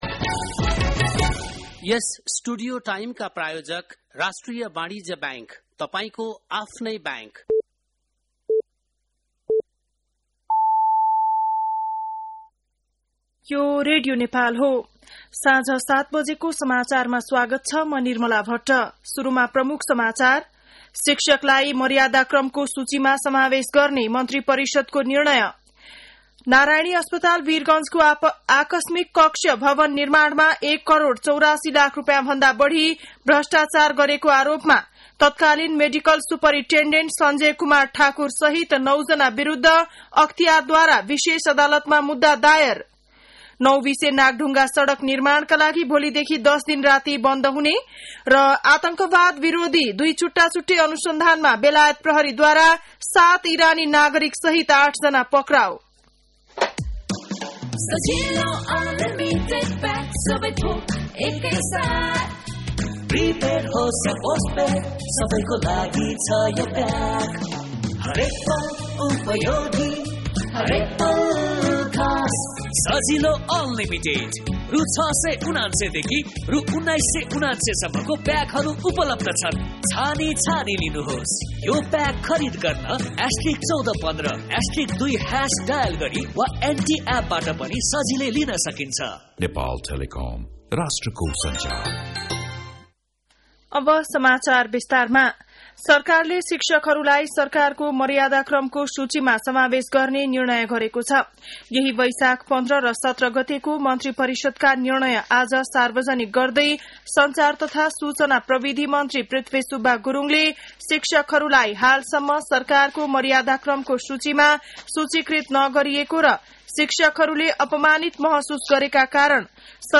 बेलुकी ७ बजेको नेपाली समाचार : २१ वैशाख , २०८२
7.-pm-nepali-news-1.mp3